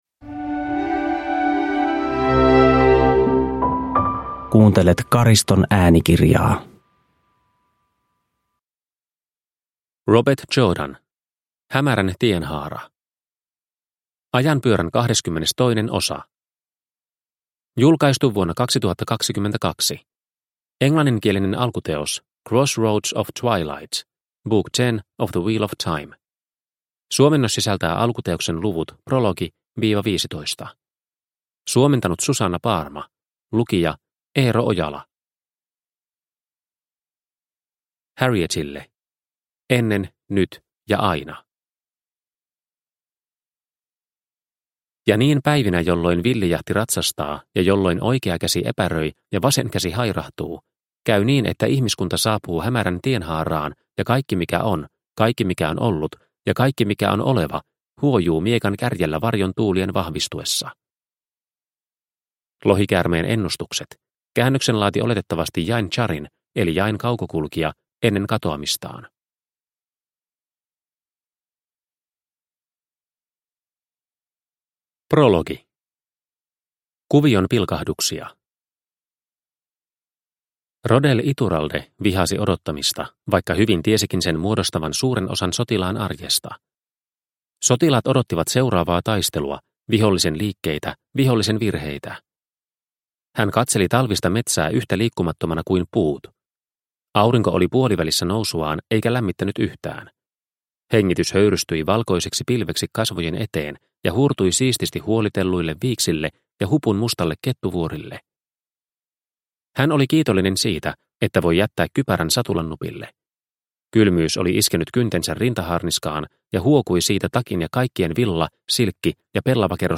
Hämärän tienhaara – Ljudbok – Laddas ner